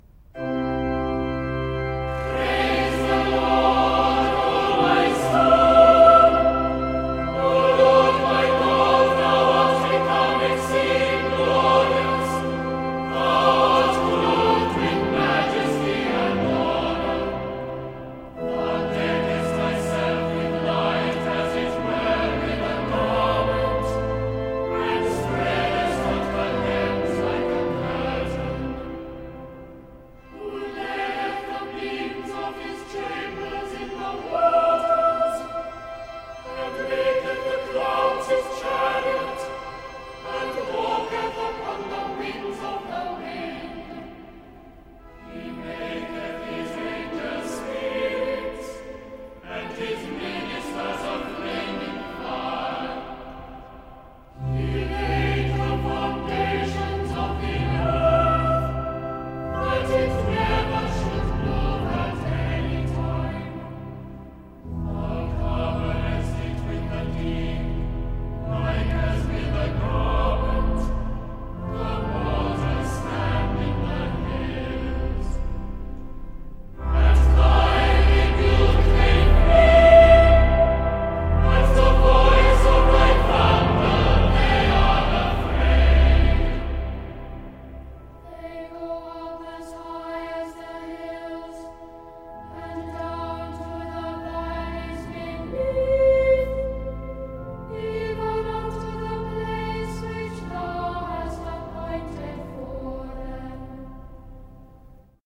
Choir-of-Westminster-Abbey-Walter-Parratt-Psalm-104-Praise-the-Lord-O-My-Soul-2.mp3